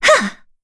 Yuria-Vox_Attack5_kr.wav